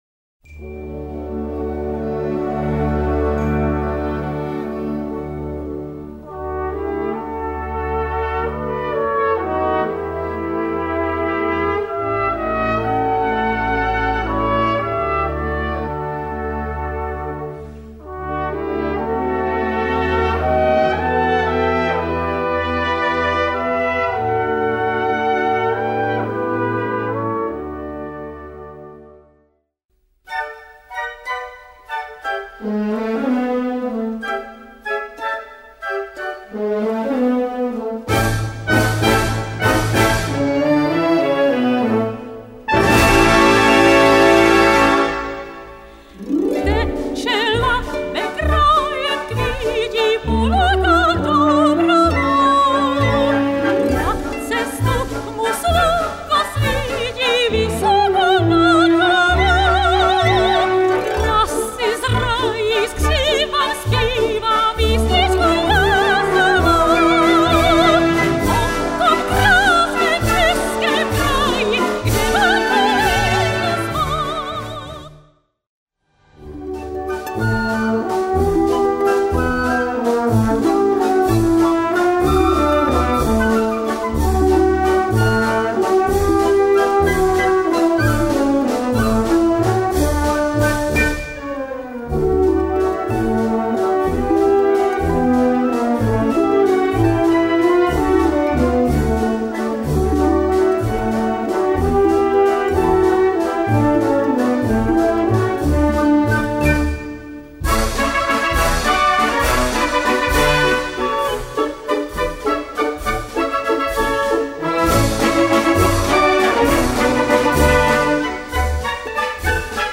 Gattung: Konzertwalzer
Besetzung: Blasorchester
Großer Konzertwalzer
Mit dem besonders melodiösen Konzertwalzer